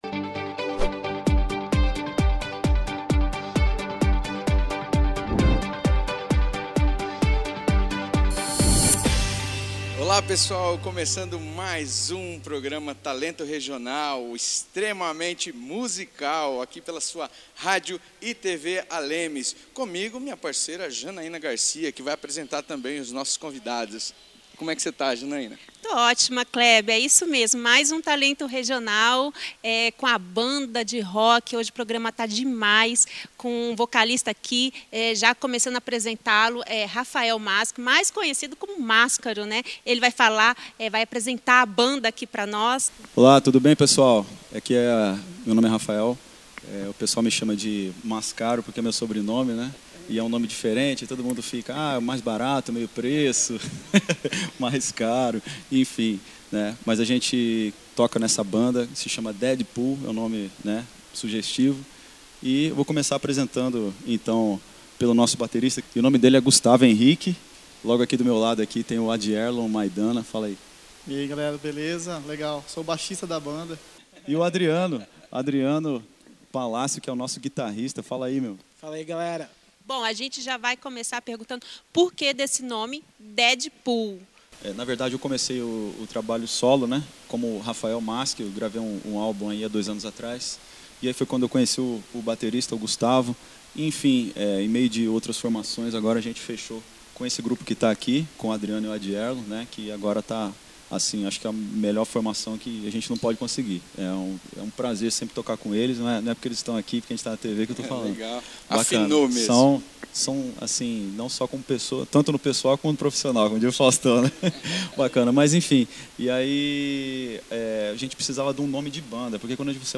O programa será com a banda de rock DEDPUL